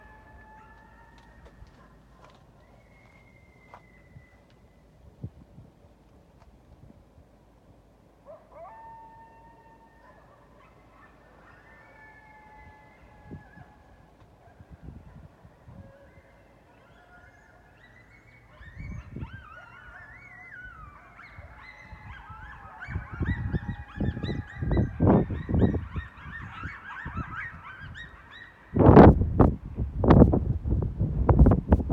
This is what the outside of Iowa at 5 AM sounds like
the sound you hear is wind and coyotes